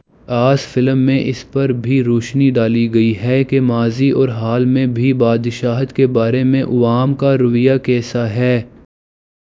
deepfake_detection_dataset_urdu / Spoofed_TTS /Speaker_06 /275.wav